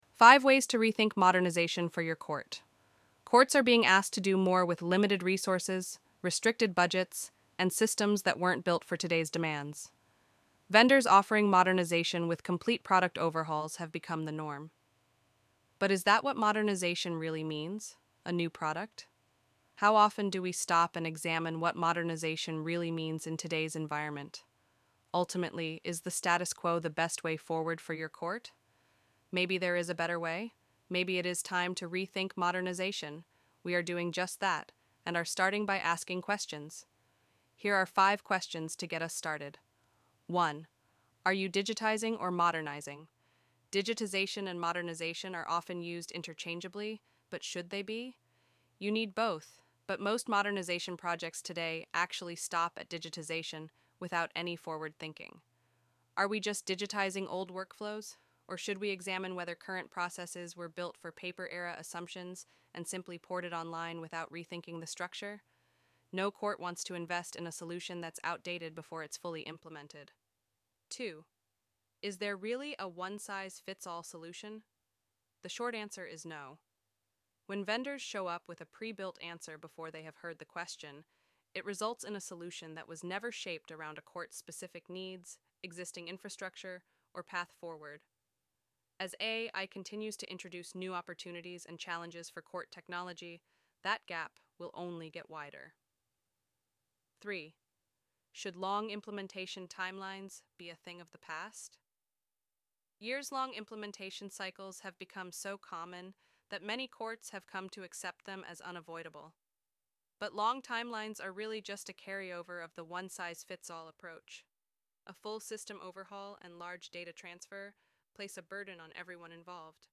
If you don't have time to read the PDF, you can use our text-to-speech feature instead.